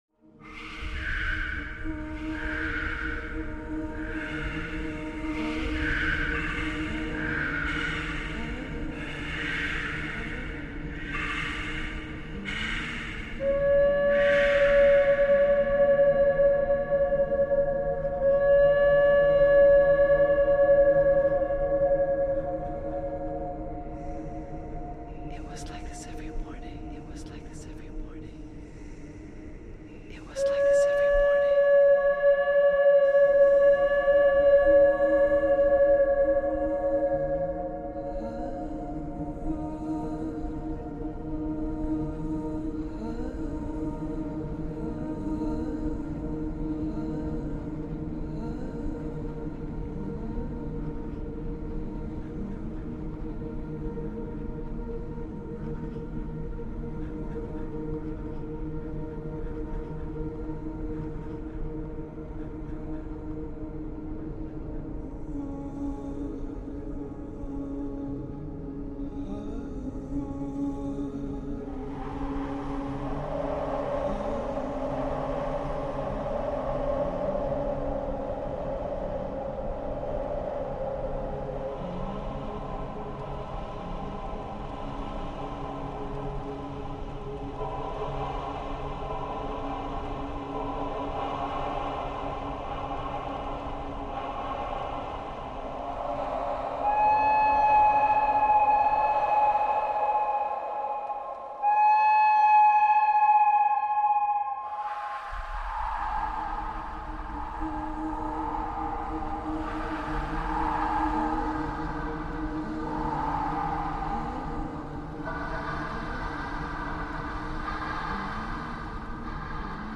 ::: an exercise in spinning thread from recorded sound fragments & then weaving these individual strands into twisted cords ::: an exercise in chance-infused, text-based, generative composition.
mctvox1_twist_all_thread_002_echo.mp3